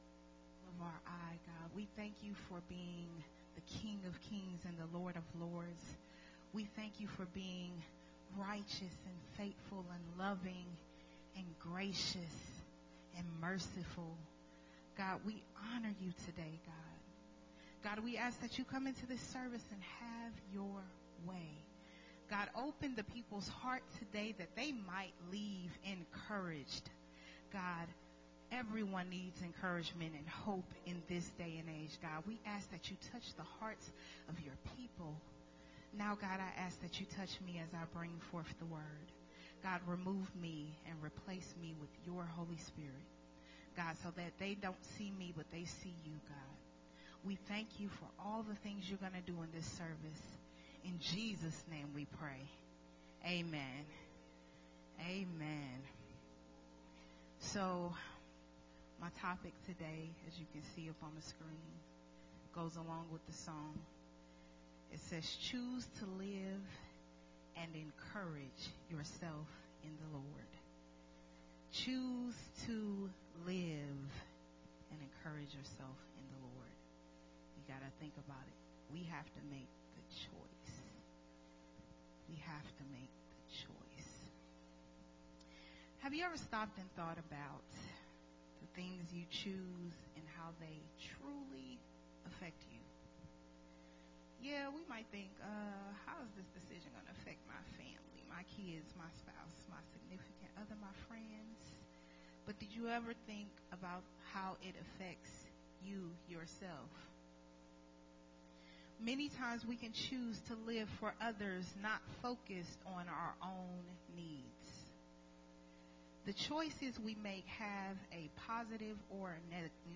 a sermon
recorded at Unity Worship Center on March 6th, 2022.